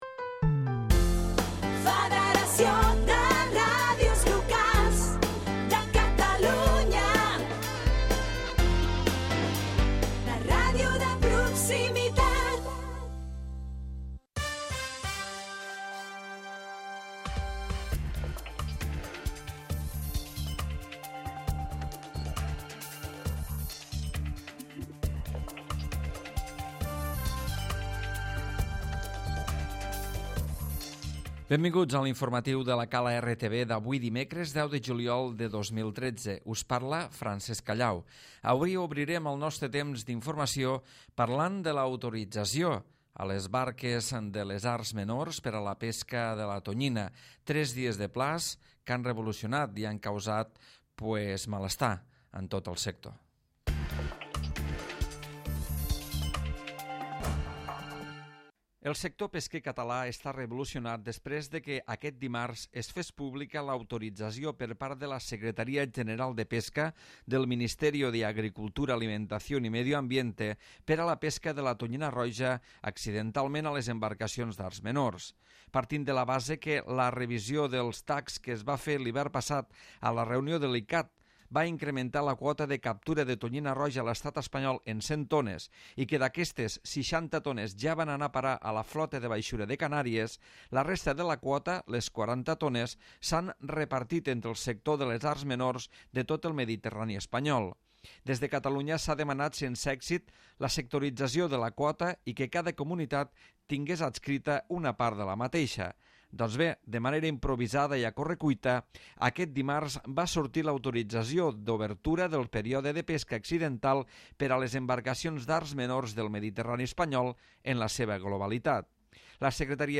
Butlleti informatiu